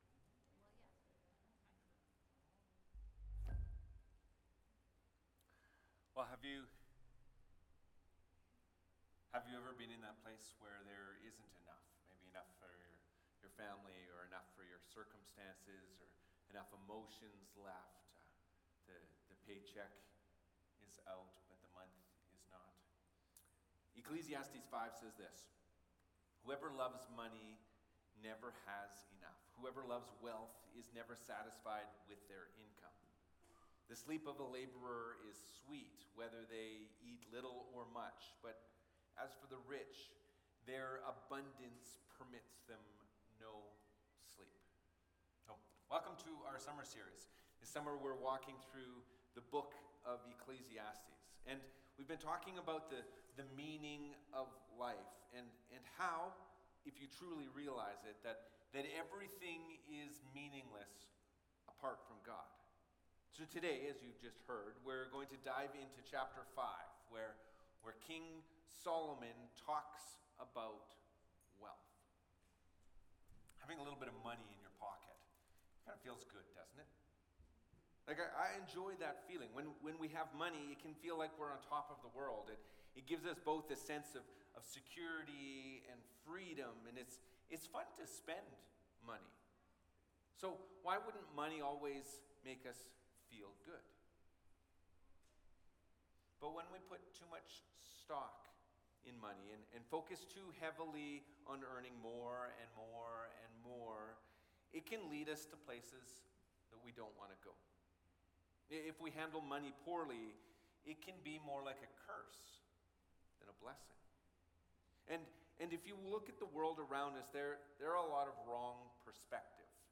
Sermons | Five Corners Church